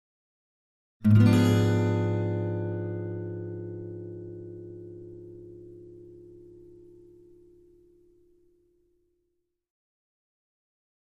Acoustic Guitar - Minor Chord 1 - Septa Chord (7)